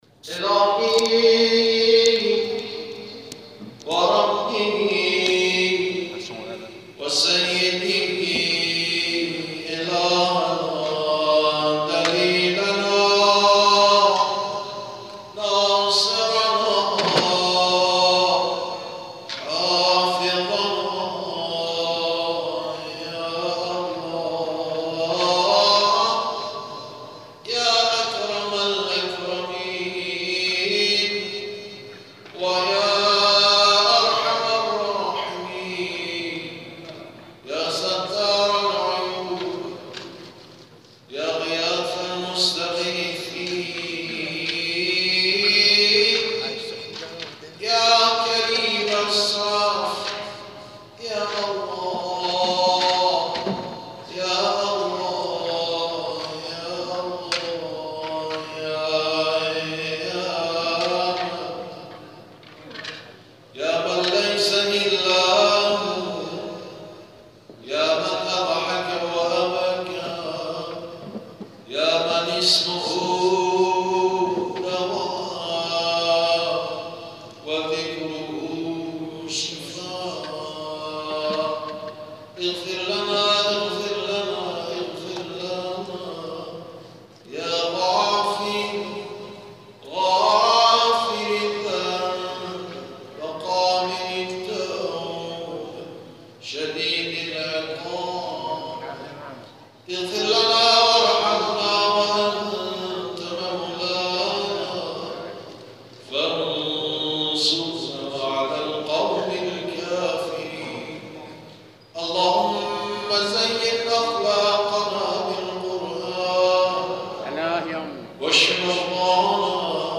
دعای ختم قرآن